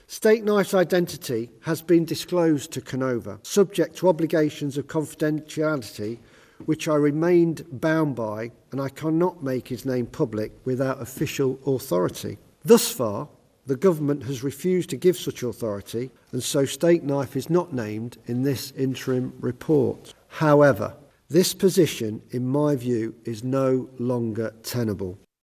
However. Jon Boutcher, who led the investigation before becoming PSNI Chief Constable says he still can’t be formally named…………………